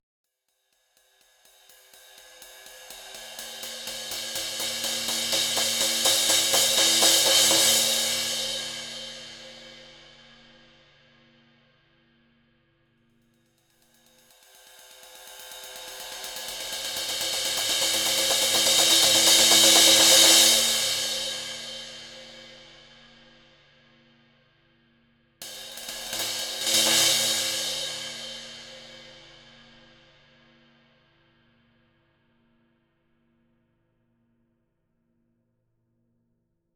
Честная библиотека барабанной установки с записью на 12 микрофонов.
Записано все живьем в хорошей комнате.